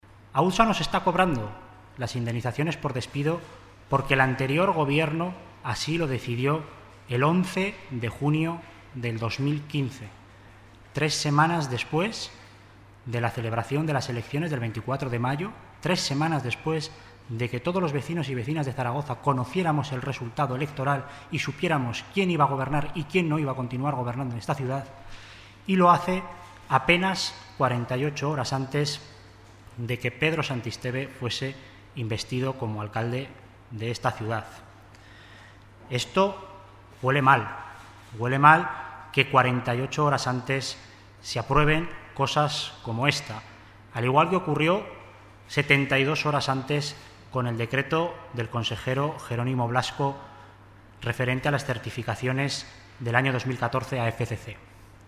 Así lo ha explicado en rueda de prensa el Consejero de Servicios Públicos y Personal, Alberto Cubero, quien ha calificado de "vergonzosa" esta situación y ha dicho que "este Gobierno no admite el pago de despidos con dinero público, en una decisión adoptada, además, en el último minuto de la anterior legislatura".